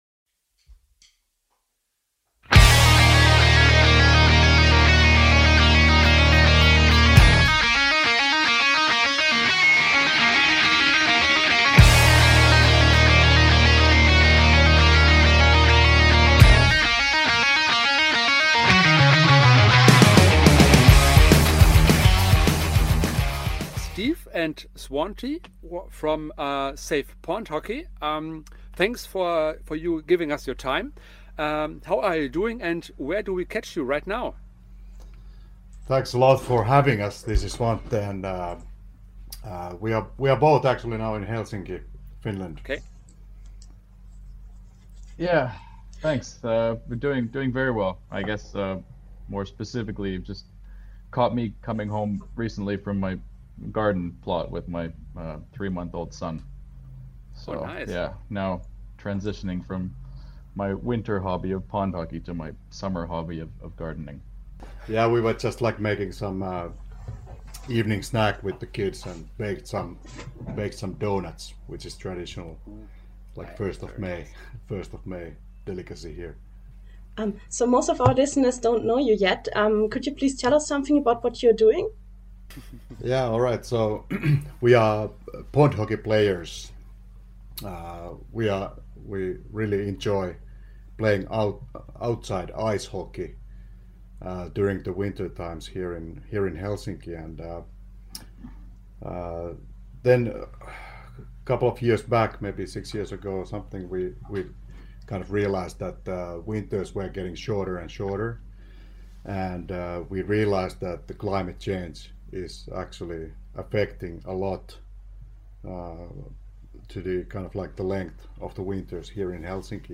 Bully #036 Save Pond Hockey im Interview